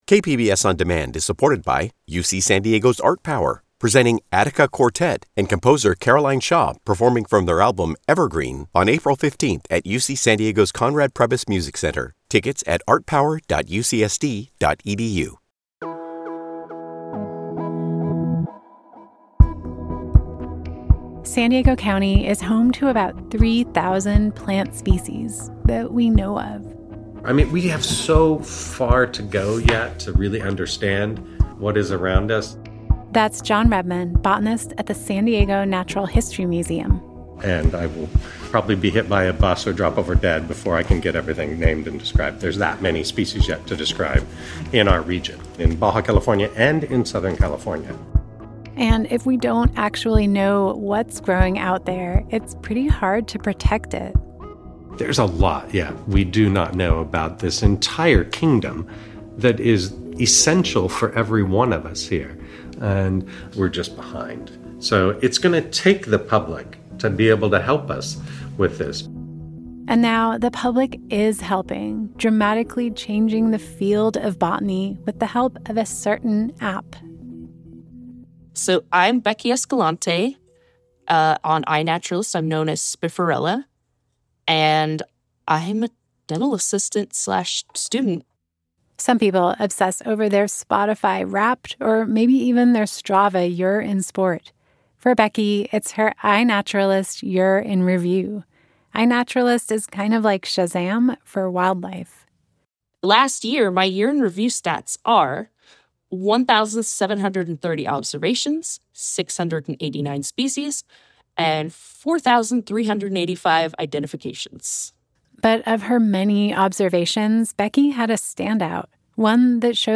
We visit the San Diego Natural History Museum , head into a rare salt marsh along Mission Bay and join a community identification party to see how this grassroots effort is reshaping science in real time.